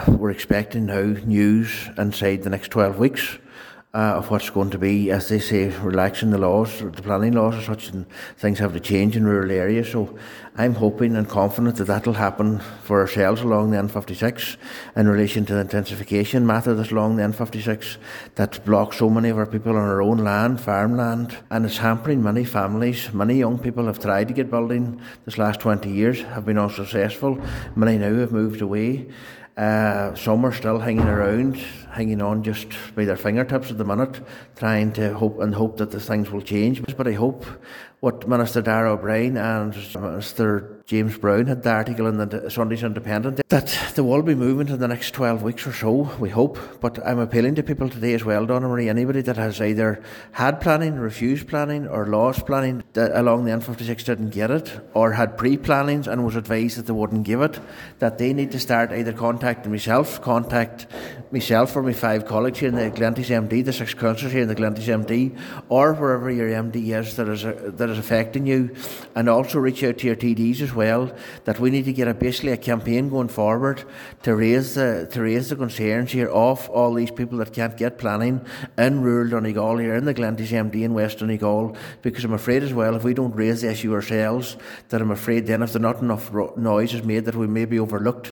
Planning on the N56 was at the top of the agenda at this morning’s Glenties Municipal District meeting.
Cllr McClafferty said across the board, there should be no grey areas: